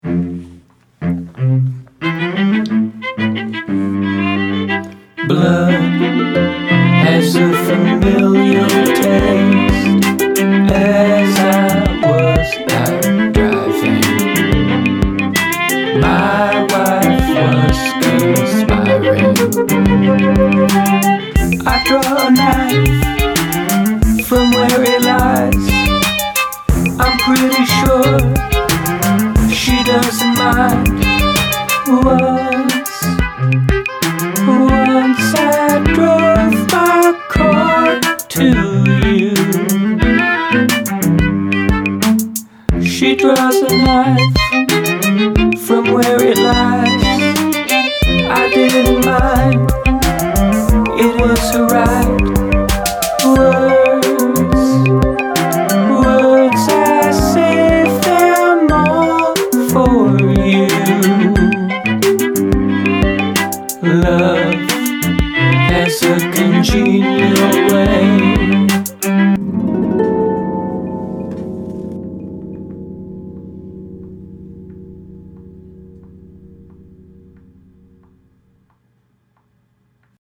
verse or: A, B, C, D, C, D, A
I created the music first by dragging samples into logic.